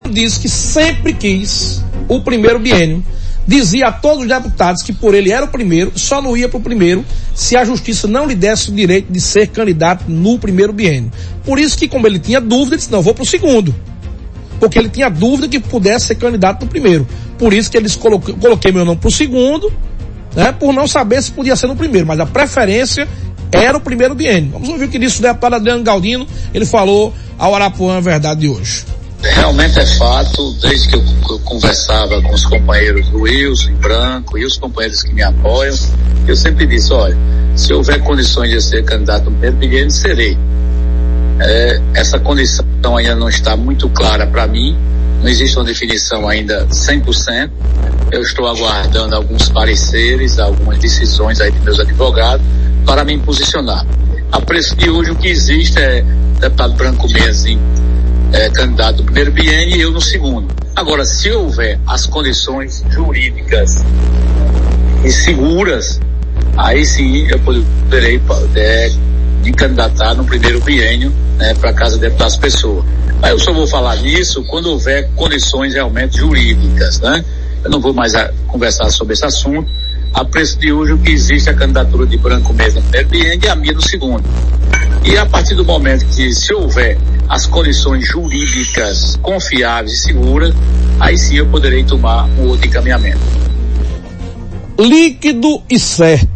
O deputado estadual Adriano Galdino, do Republicanos, que também é o atual presidente de Assembleia Legislativa da Paraíba (ALPB), confirmou, em entrevista nesta segunda-feira (26), que deve disputar o comando da Casa nos dois biênios e não apenas no segundo.